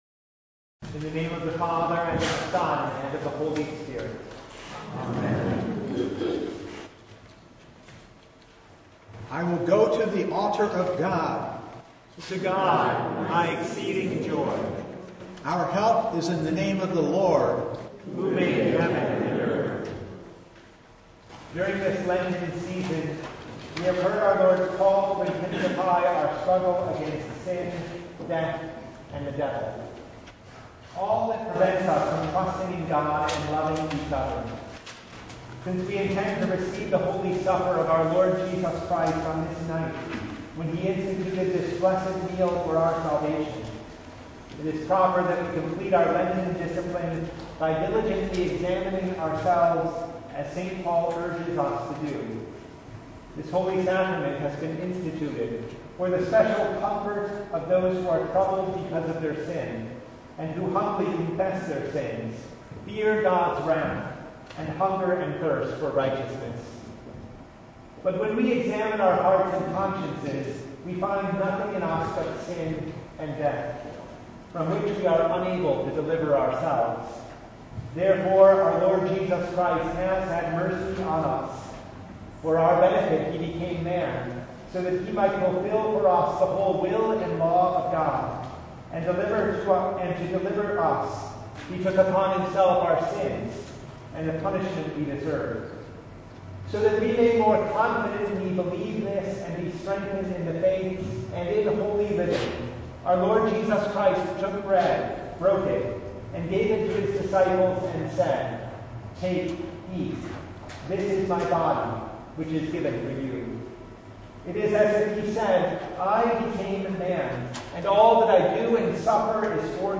Passage: Matthew 26:17-30 Service Type: Maundy Thursday
Full Service